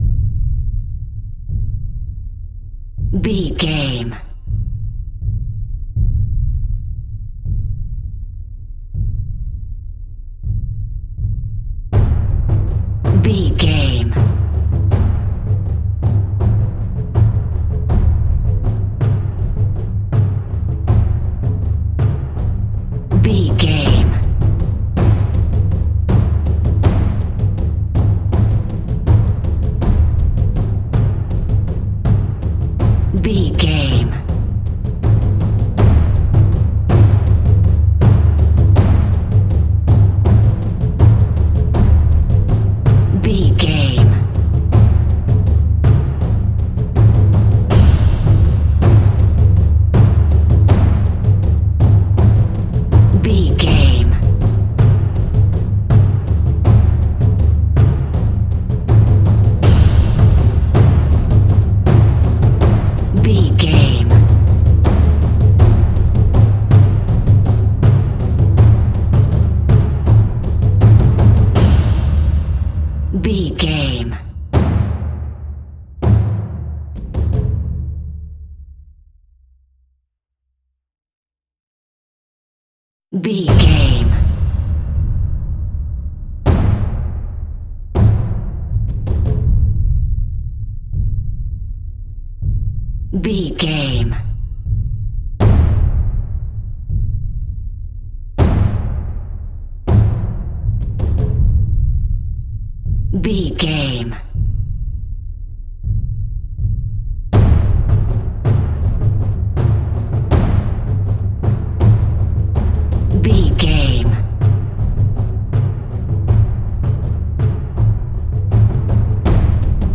Exotic and world music!
Atonal
D
world beat
taiko drums
timpani